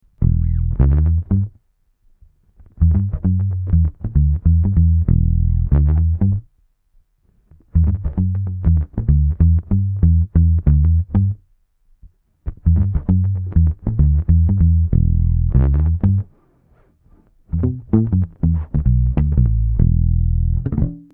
Palm Mute P-Bass
An AVP BTW P-Bass straight into the DI02.
DI02 - P-Bass Palm Mute - BassTheWorld.mp3